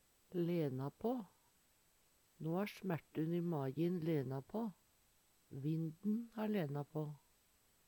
lene på - Numedalsmål (en-US)
DIALEKTORD PÅ NORMERT NORSK lene på veret har stilna eller ha mindre vondt frå smerte Eksempel på bruk No ha smertun i majin lena på.